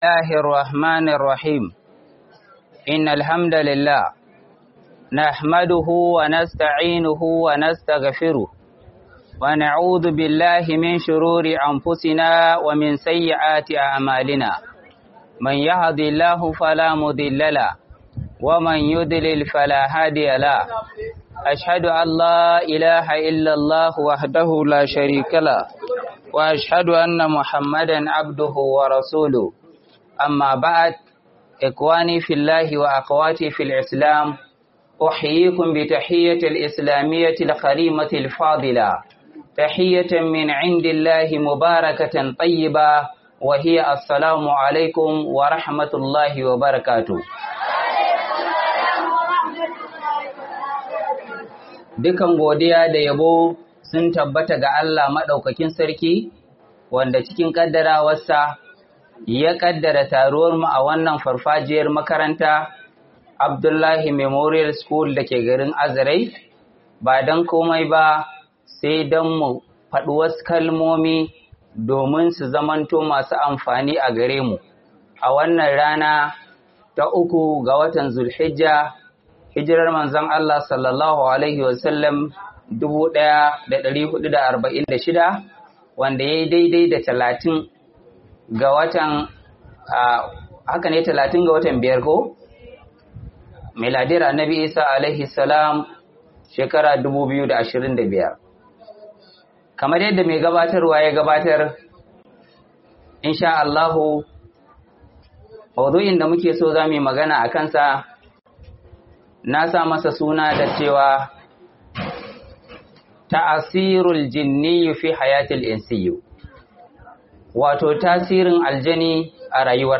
Muhadara by Jibwis Katagum LGA